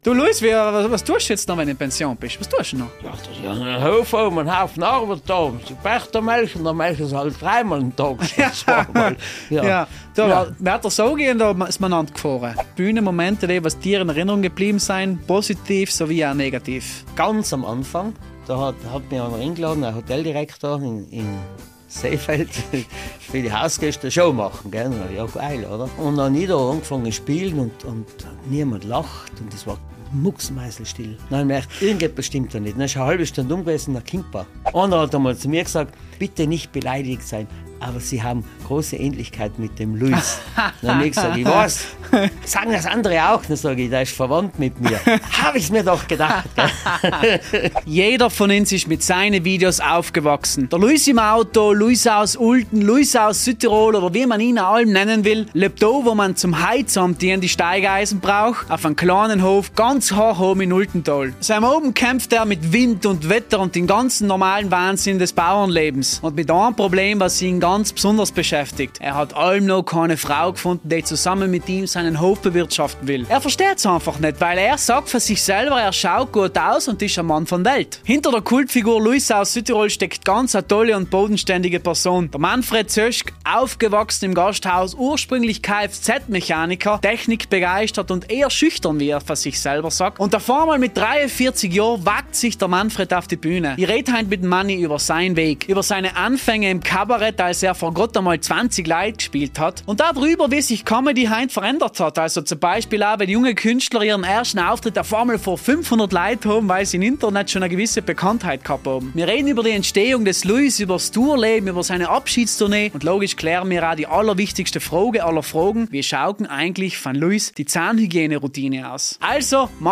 In dieser Episode von „Das Gespräch“ spreche ich mit Manfred Zöschg – vielen besser bekannt als Luis aus Südtirol.